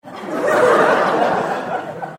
دانلود صدای خنده دسته جمعی 2 از ساعد نیوز با لینک مستقیم و کیفیت بالا
جلوه های صوتی